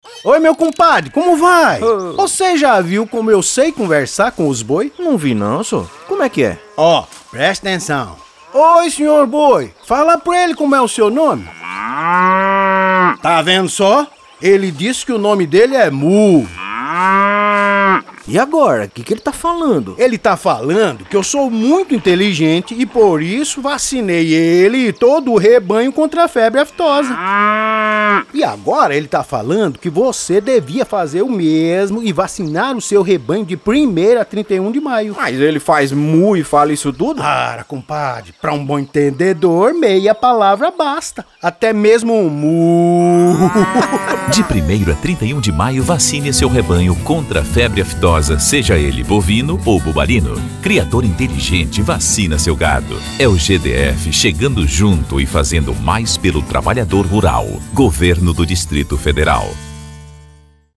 Spot Febre Aftosa ● GDF